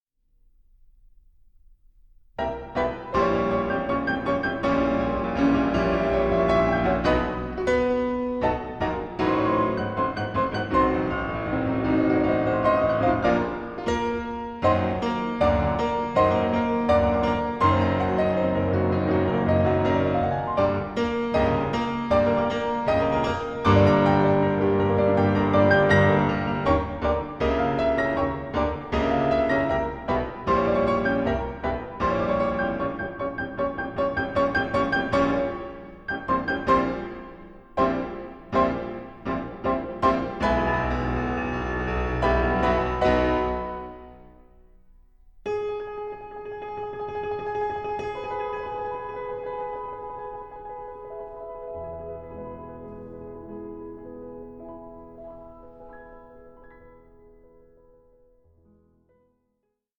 Suite for Piano Duo